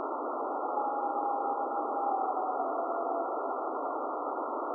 Washington_call.wav